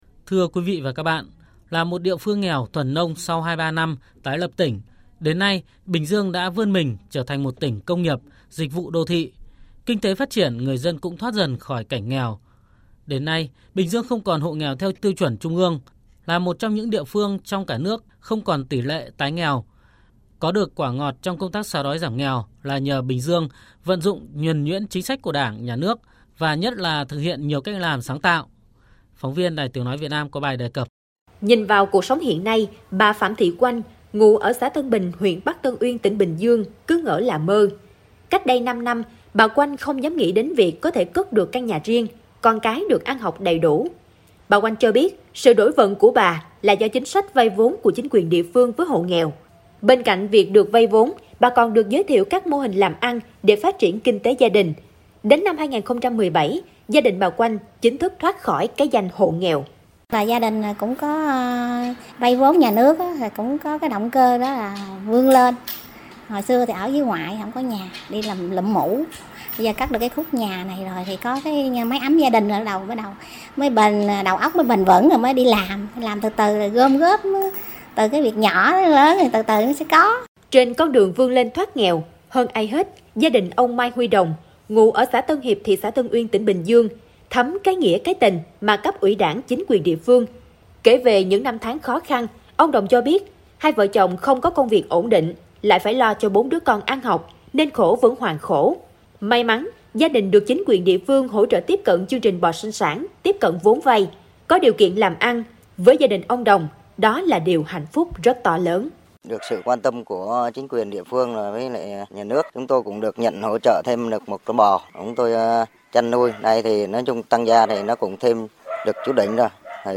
30 Phóng sự giảm nghèo